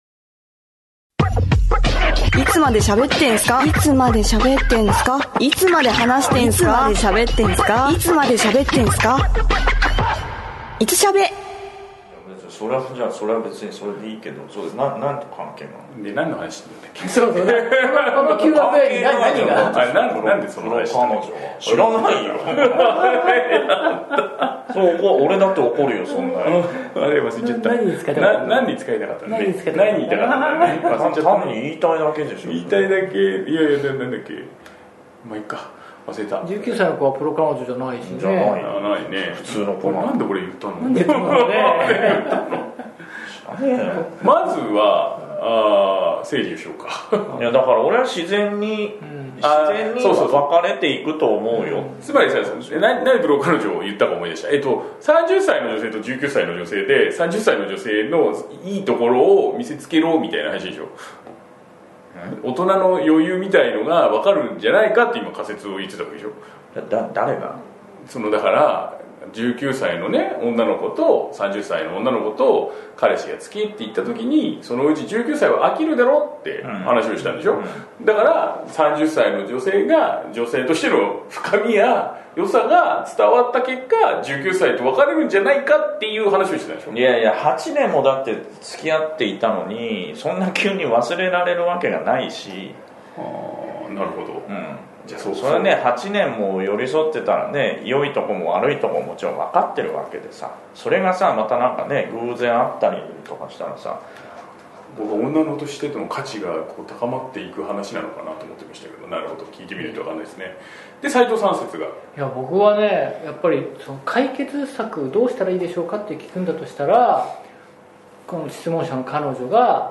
４０代半ばの同級生おっさん達による、気ままなトーク番組「いつまでしゃべってんすか～いつしゃべ」！今回も3人でお届けします！